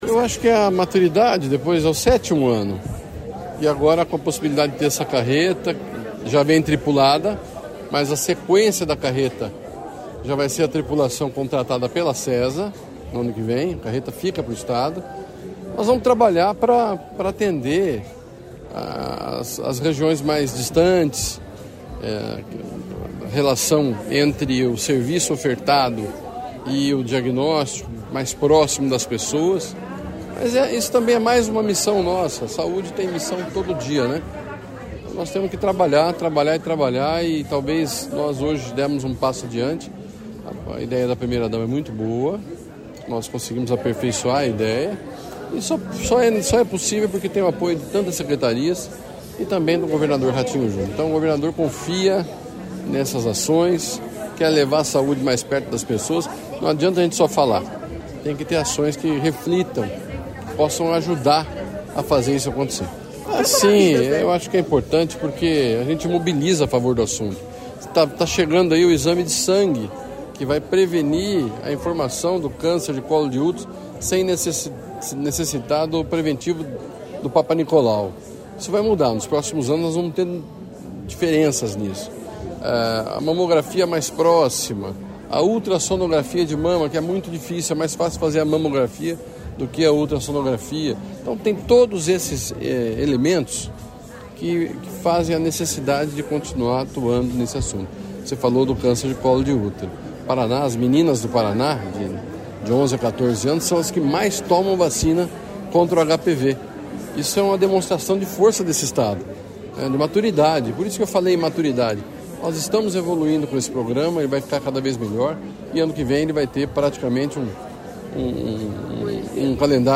Sonora do secretário de Estado da Saúde, Beto Preto, sobre o lançamento do campanha Paraná Rosa de 2025